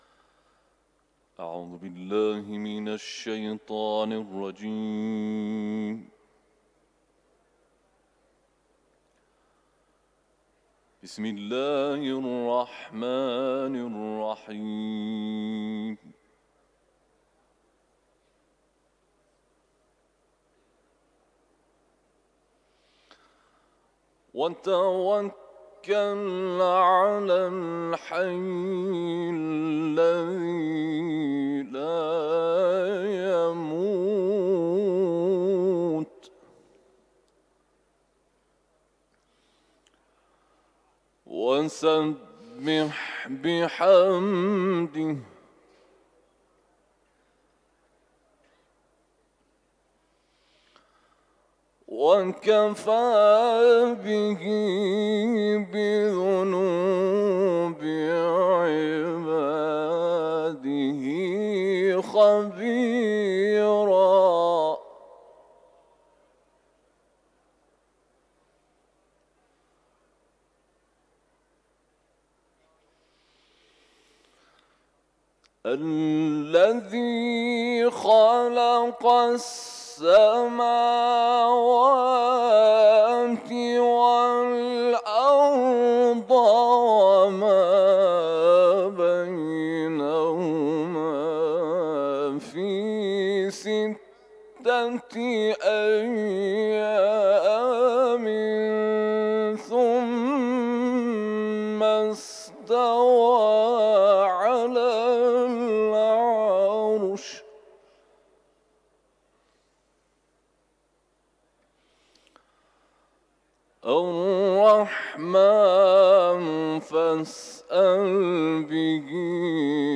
تلاوت
حرم مطهر رضوی ، سوره فرقان